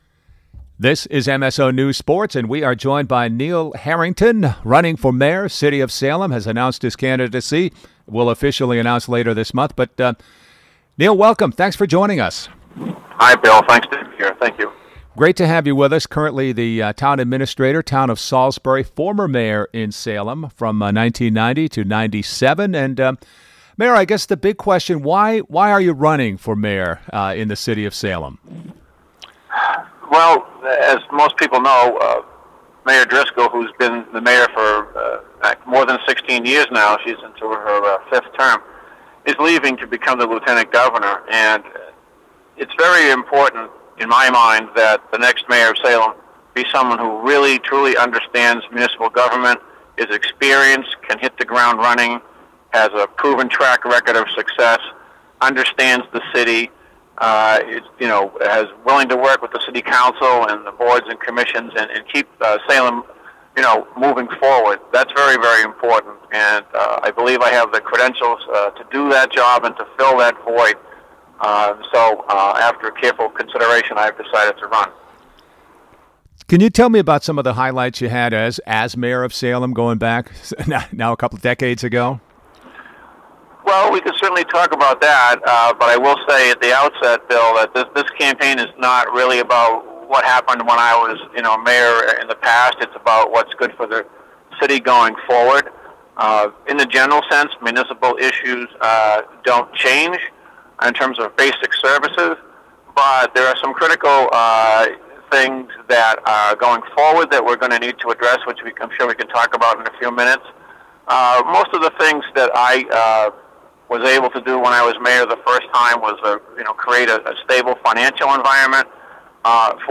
In this podcast interview Harrington shares insights on the importance of local city and town government in the lives of residents and discusses key issues facing Salem such as affordable housing and the public schools. Harrington also reflects back on his years as Salem Mayor (1990-97) and the issues facing the city at that time and several of the key accomplishments during his time in office.